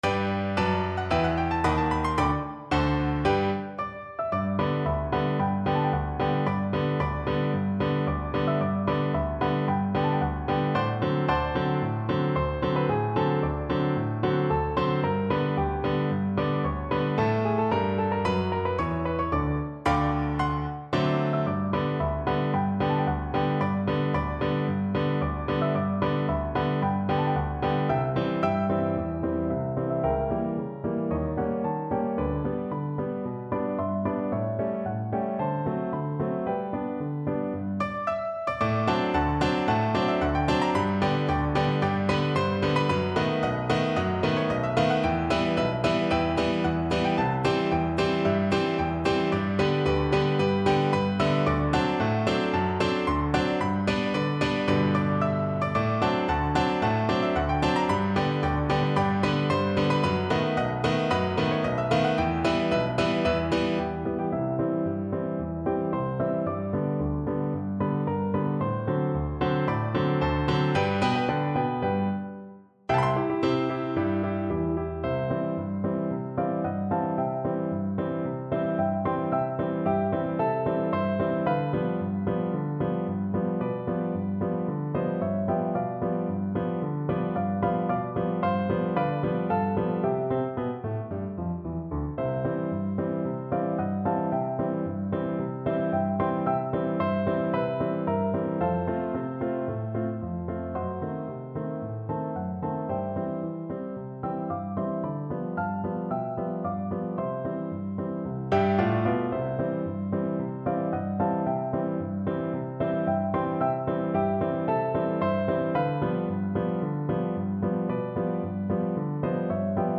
No parts available for this pieces as it is for solo piano.
G major (Sounding Pitch) (View more G major Music for Piano )
March =c.112
2/2 (View more 2/2 Music)
Piano  (View more Intermediate Piano Music)
Classical (View more Classical Piano Music)